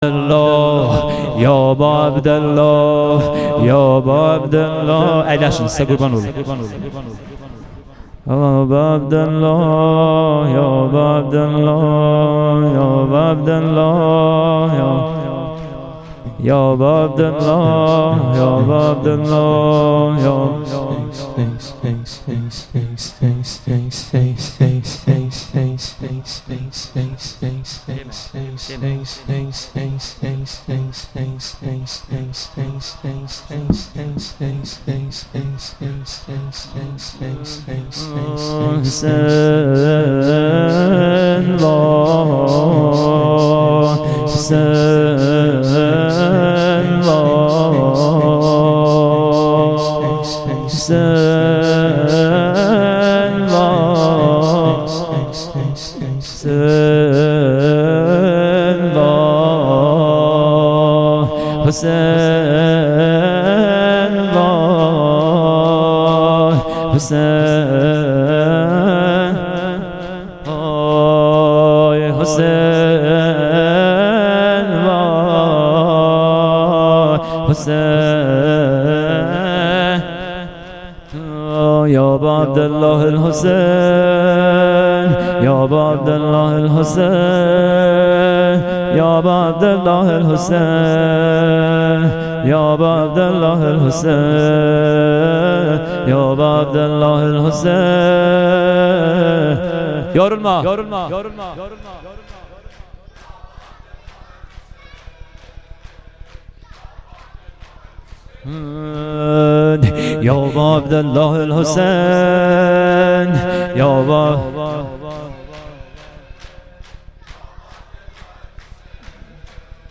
شب دوم محرم 98 - بخش پایانی سینه زنی و شور